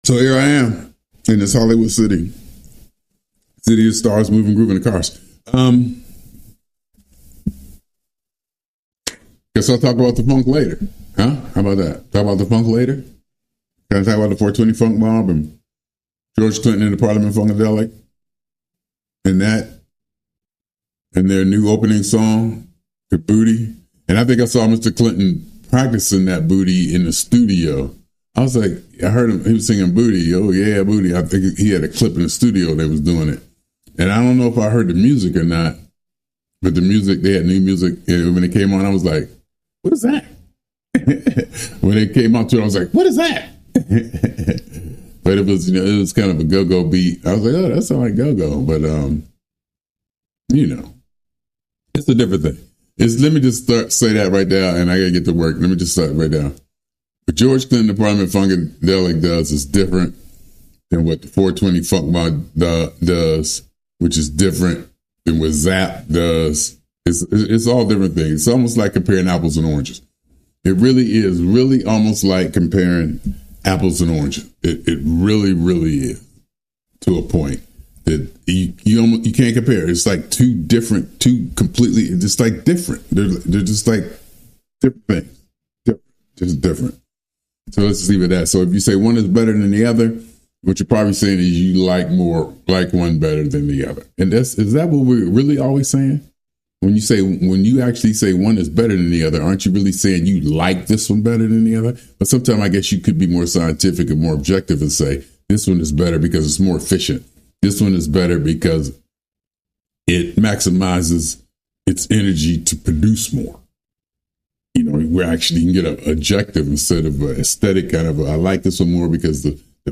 In this talk